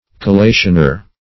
Search Result for " collationer" : The Collaborative International Dictionary of English v.0.48: Collationer \Col*la"tion*er\, n. (Print.) One who examines the sheets of a book that has just been printed, to ascertain whether they are correctly printed, paged, etc. [Eng.]